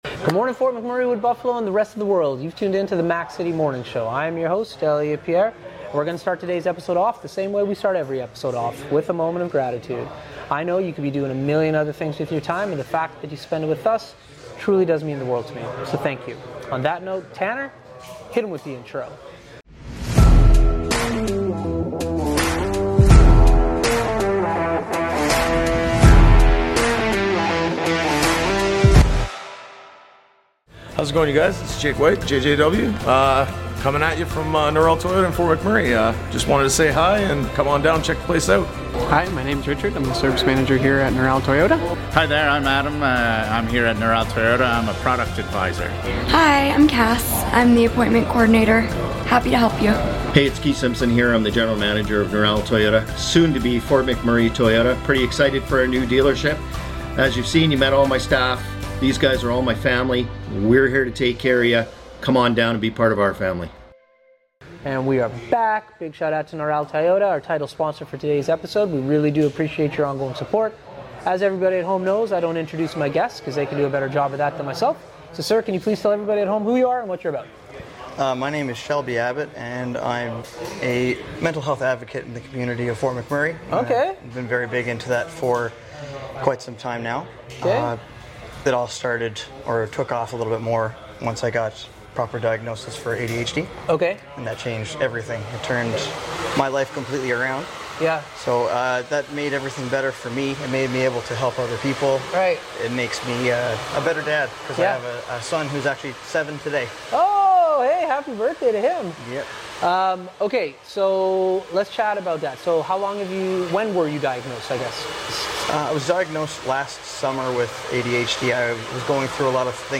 We are back on location at Thickwood Barbershop today